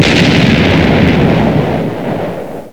explosion sound effect free sound royalty free Memes